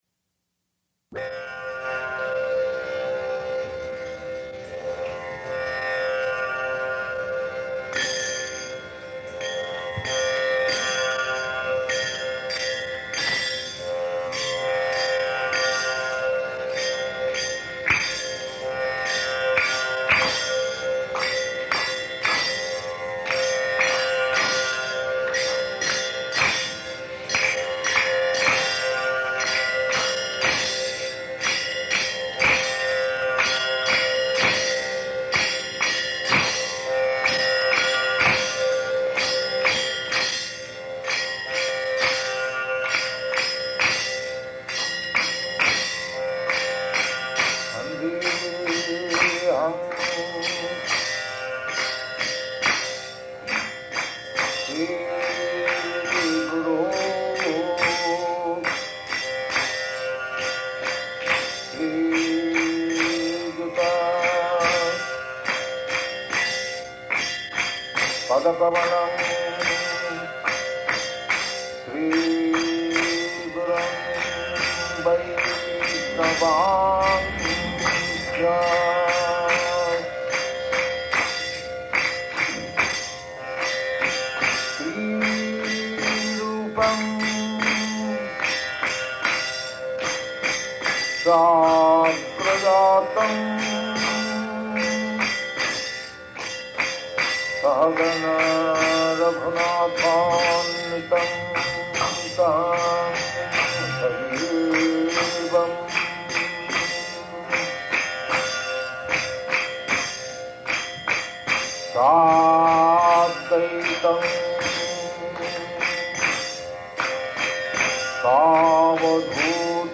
Location: San Francisco
[chants maṅgalācaraṇa prayers]